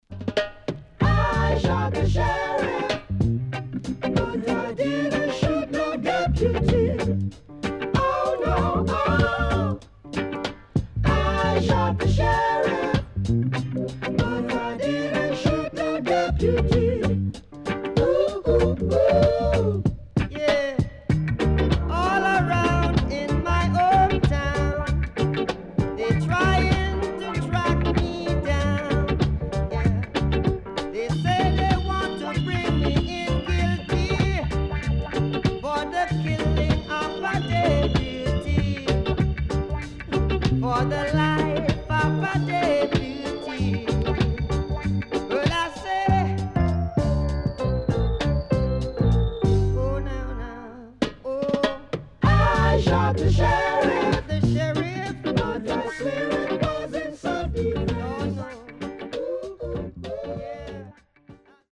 Reissue Used -【12inch】
SIDE A:少しチリノイズ入りますが良好です。